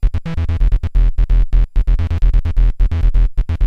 SONS ET LOOPS GRATUITS DE BASSES DANCE MUSIC 130bpm
Basse dance 2 D